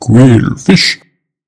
Cries
QWILFISH.mp3